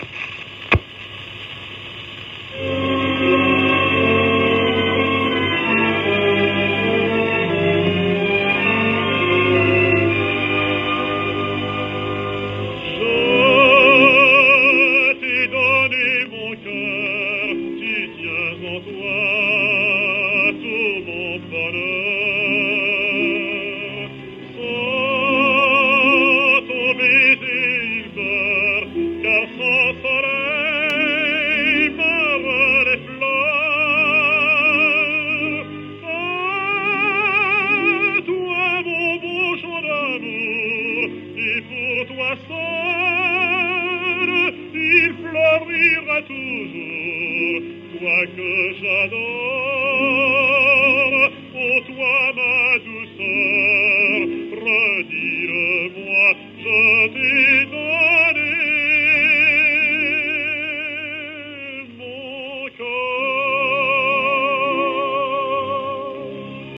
w/オーケストラ
フランスのテノール。
シェルマン アートワークスのSPレコード